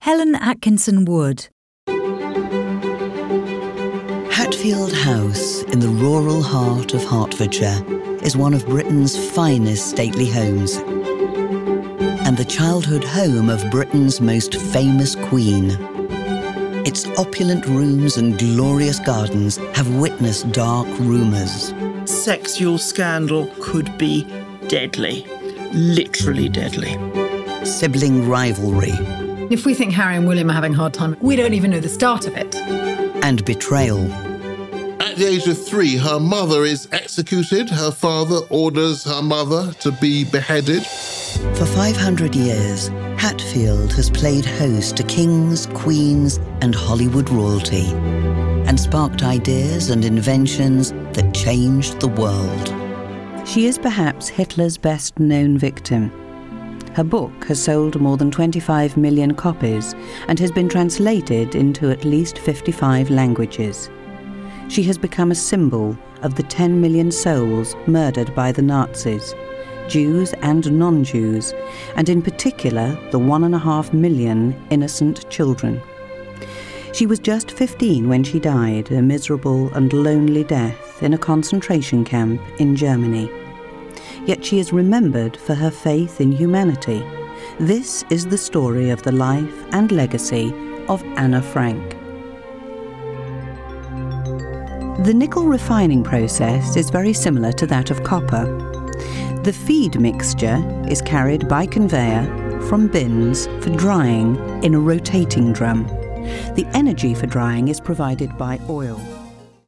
Commercial 0:00 / 0:00